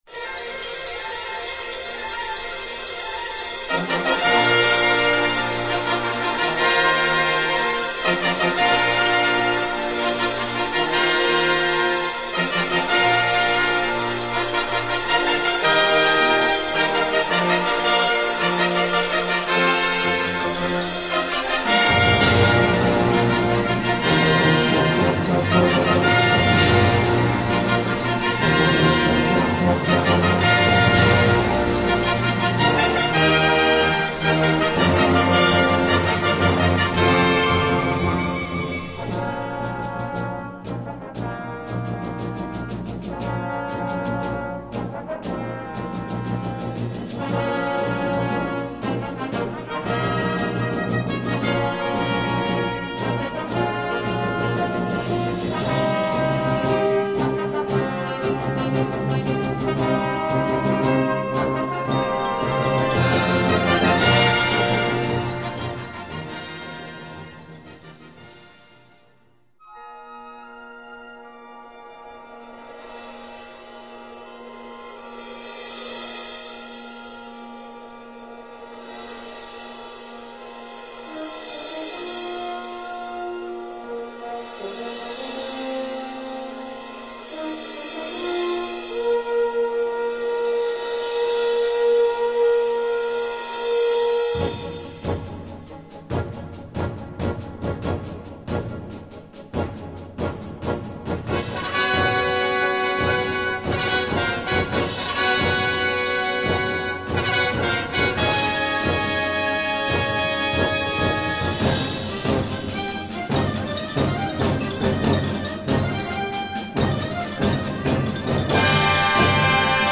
Gattung: Ouvertüre
Besetzung: Blasorchester
Dies ist Science Fiction für Blasorchester und Sprecher.